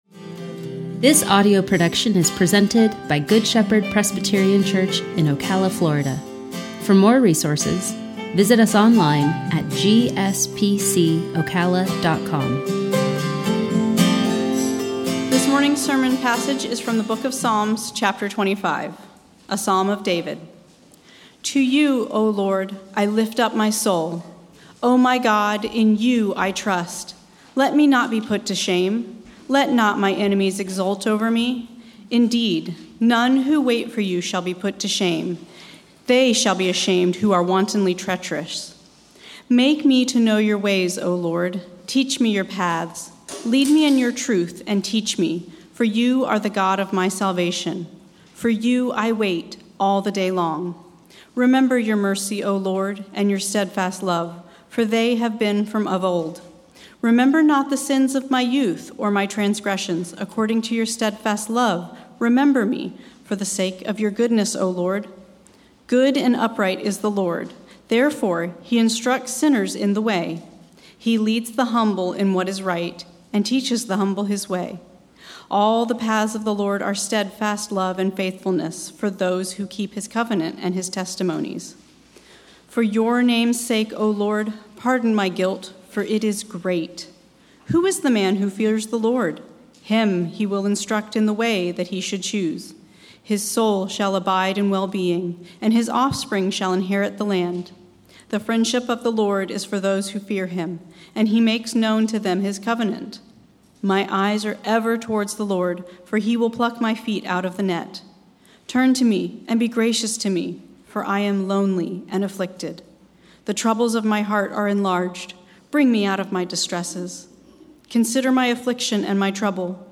sermon-8-1-21.mp3